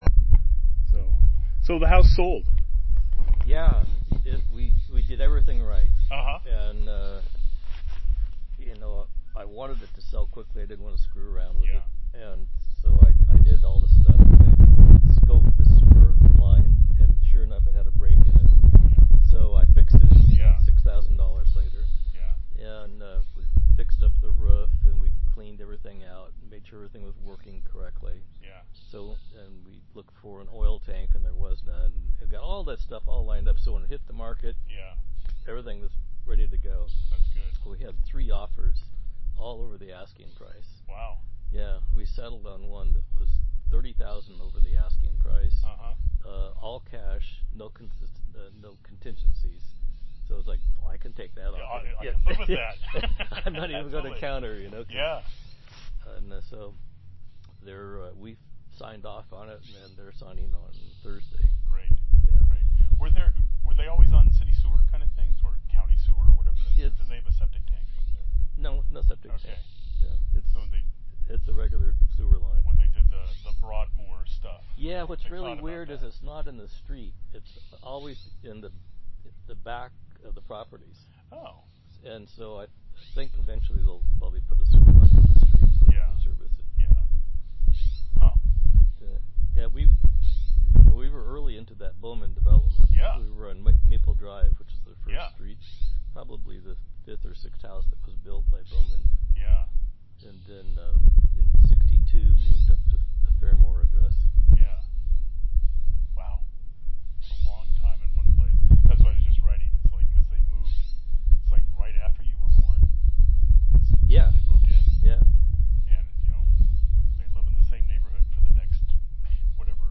An interview
After some informal chatting at the beginning of the recording